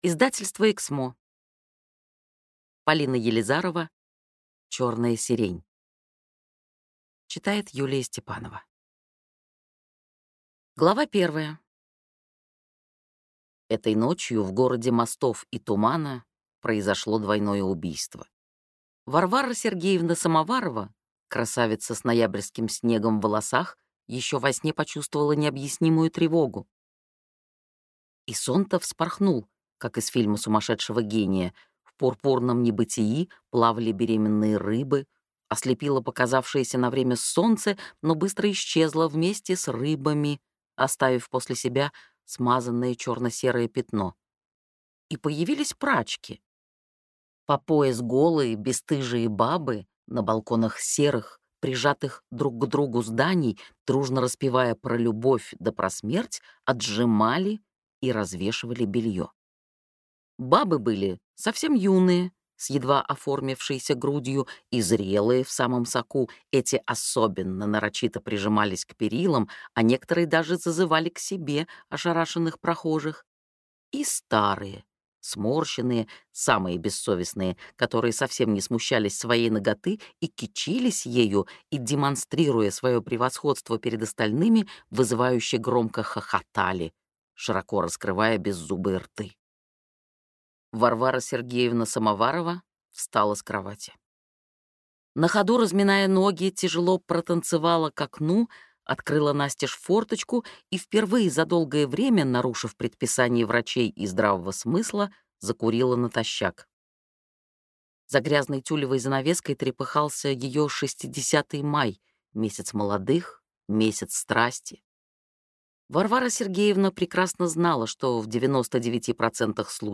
Аудиокнига Черная сирень | Библиотека аудиокниг